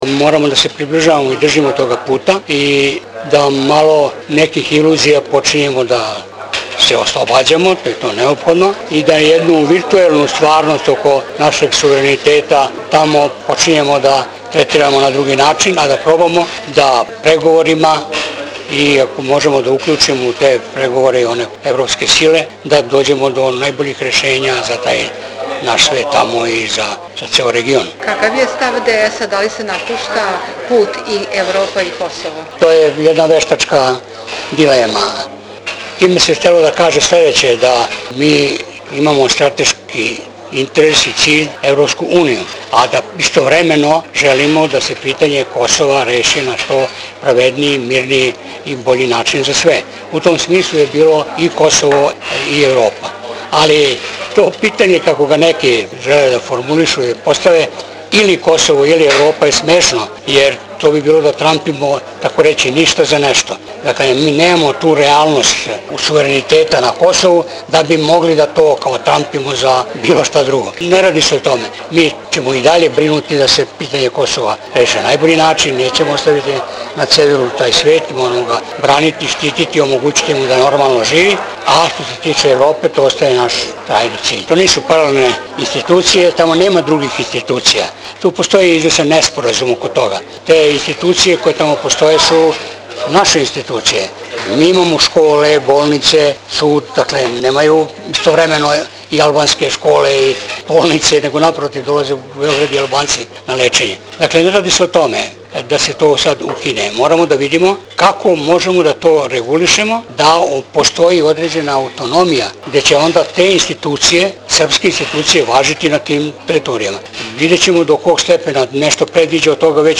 Intervju Dragoljuba Mićunovića o aktulenim problemima